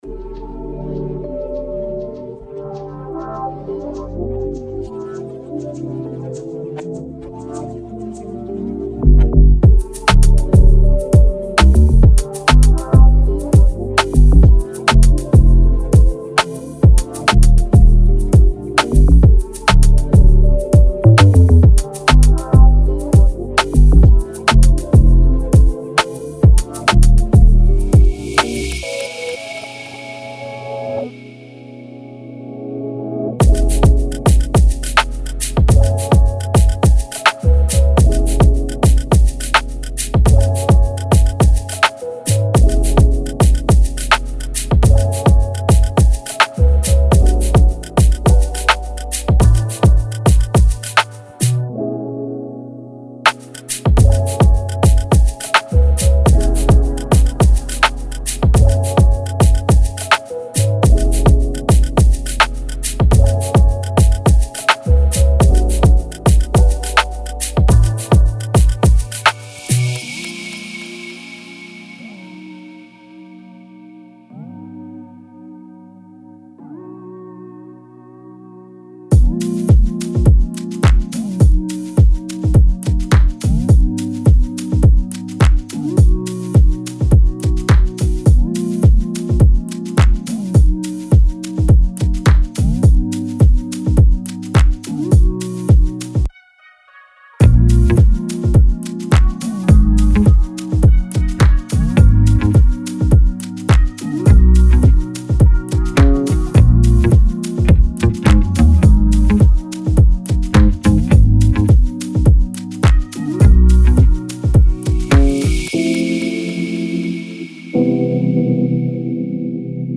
Genre:Soul and RnB
シェイカー、マラカス、ミックスパーカッションループを使って、さらに複雑なリズムを作り上げることも可能です。